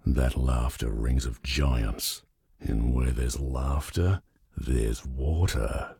B_giant_laugh.ogg